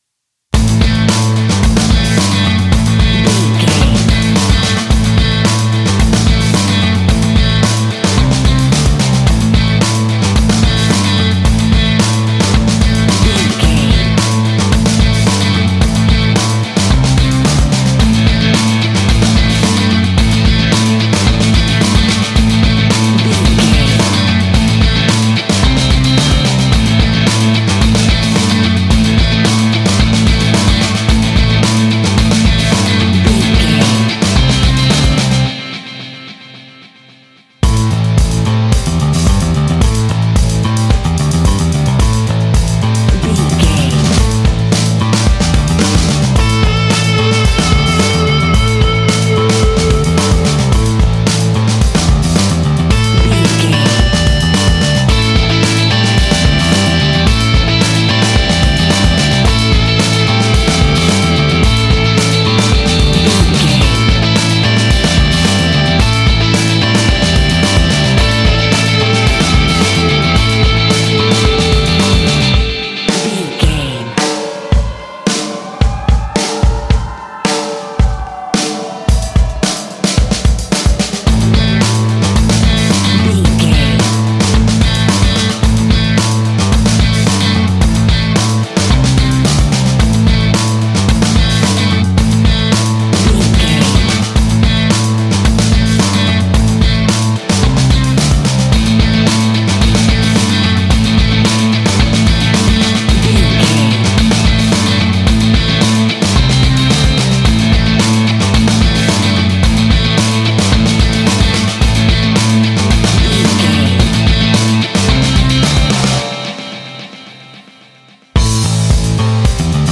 Ionian/Major
Fast
energetic
driving
happy
bright
electric guitar
bass guitar
drums
hard rock
heavy drums
distorted guitars
hammond organ